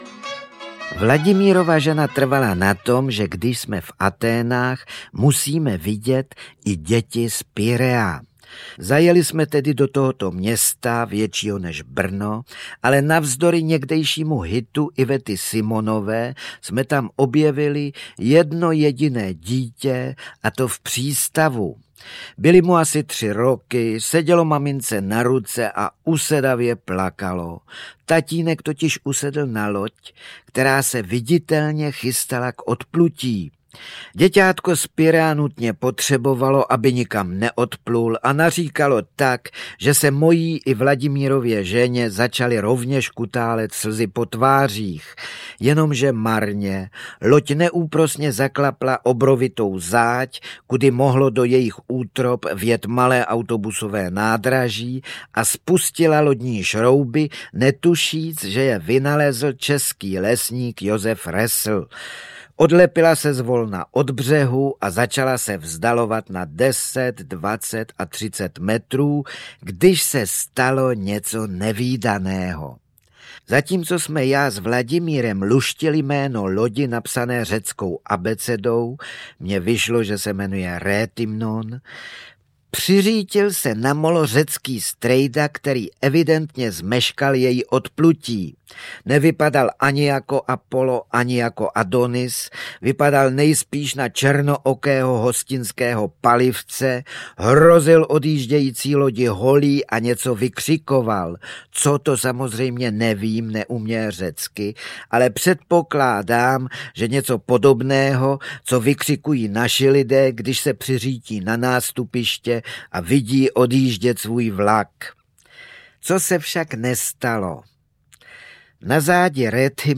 Po úspěších titulů Františka Nepila Jak se dělá chalupa a Dobré a ještě lepší jitro vychází poprvé na zvukovém nosiči archivní nahrávka z roku 1989, v níž František Nepil vypráví o svých cestovatelských zážitcích
Ukázka z knihy
• InterpretFrantišek Nepil, Ondřej Suchý